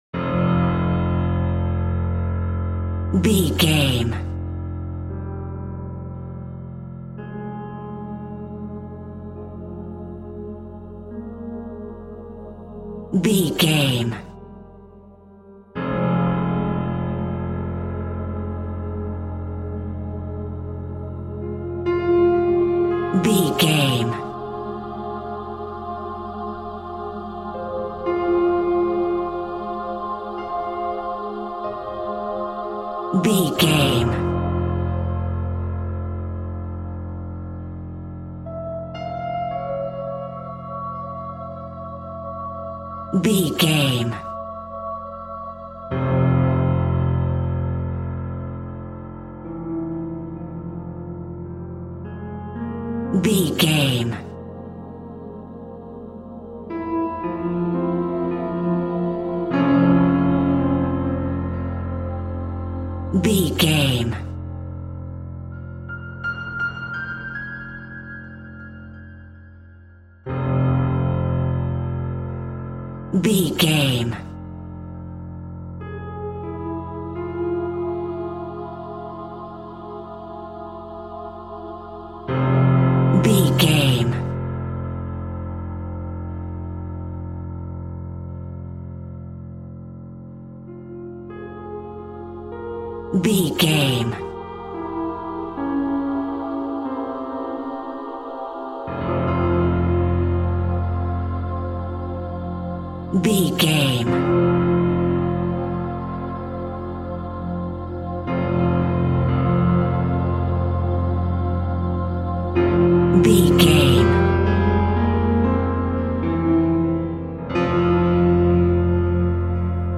Aeolian/Minor
tension
ominous
dark
haunting
eerie
piano
sythesizer
horror
pads